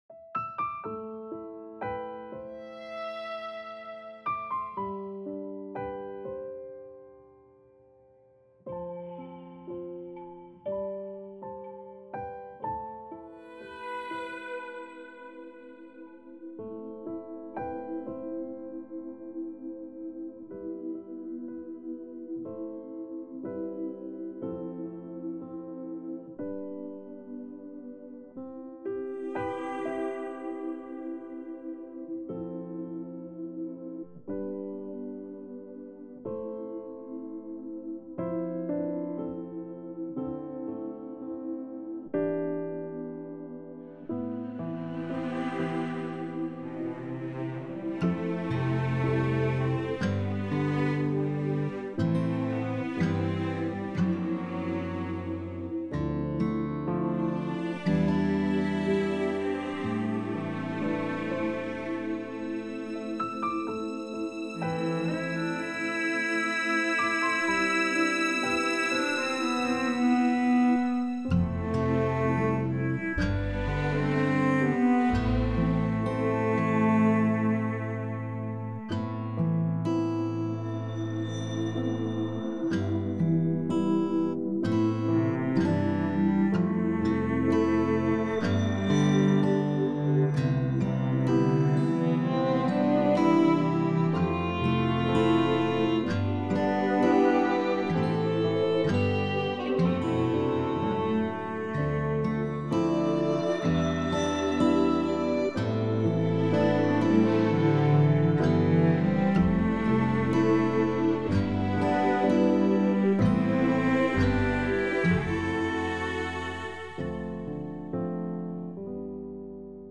mp3 伴奏音樂
電吉他/貝斯